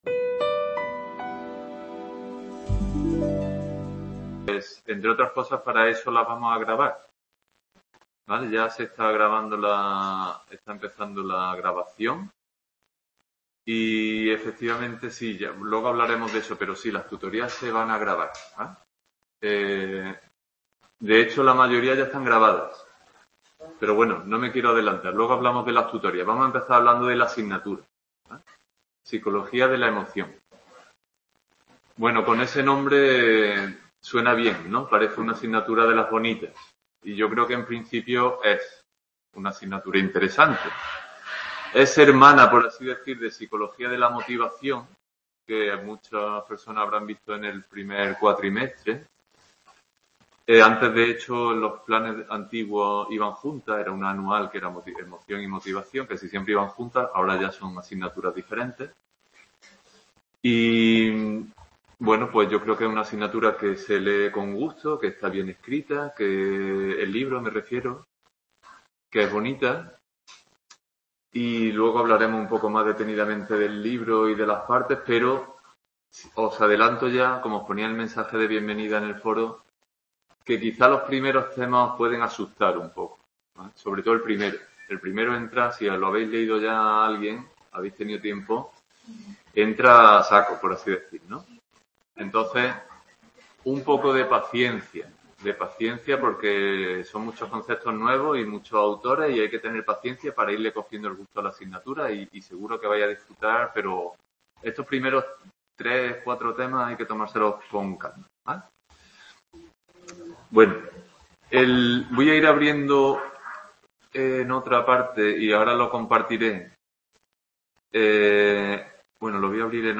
Tutoría Emoción 17-feb-22 Presentación | Repositorio Digital